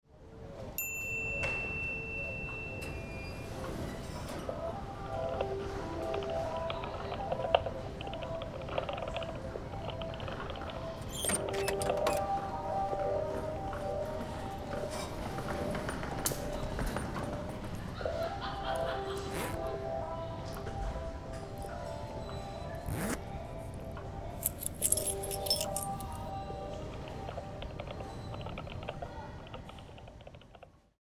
Vanligtvis fokuserar vi på det visuella och taktila, men här bad vi en ljuddesigner skapa några audiella exempel åt oss.
Sedan, en hotellobby där vi vet att många kan slappna av och arbeta trots ett ständigt sorl av bakgrundsljud.